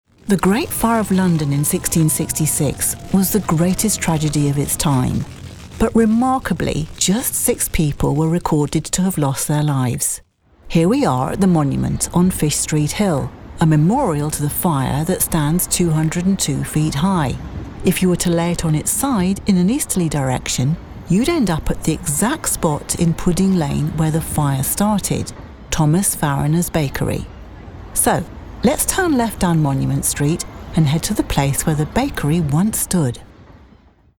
Female
English (British)
Tour Guide
Great Fire London Tour Guide
Words that describe my voice are Approachable, Reassuring, Compassionate.